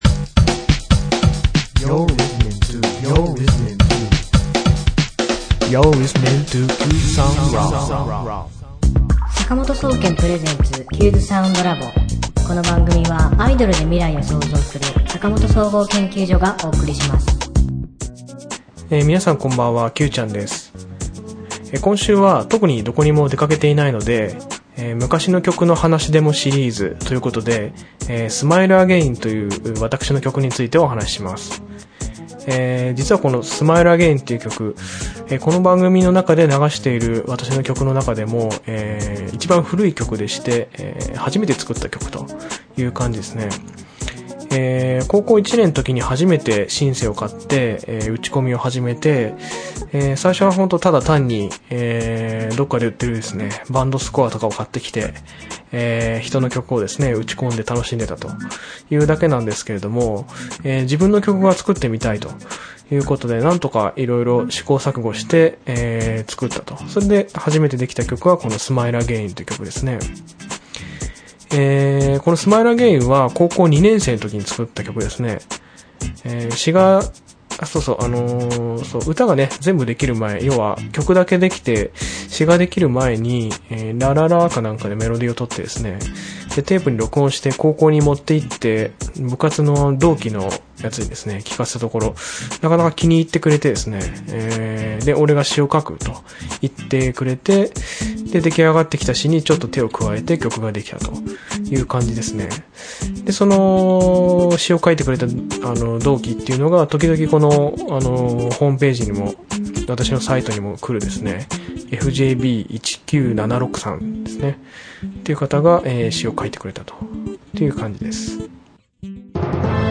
今週の挿入歌『Smile Again』